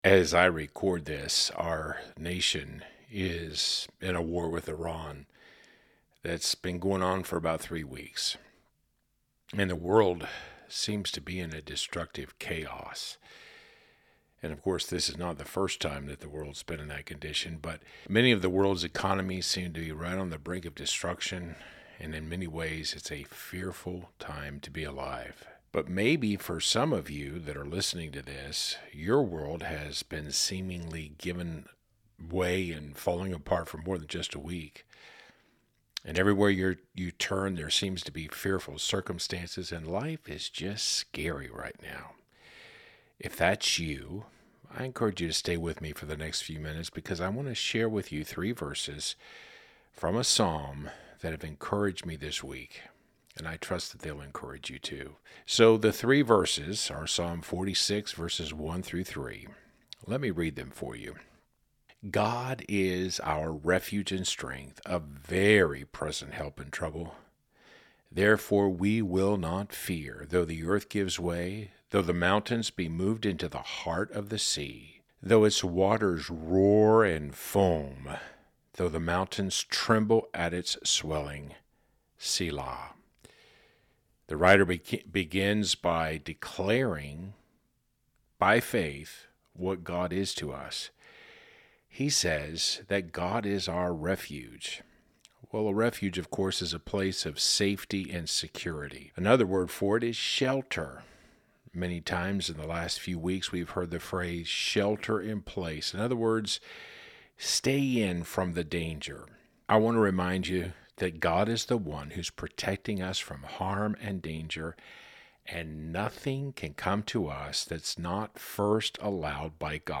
A Meditation on Psalm 46:1-3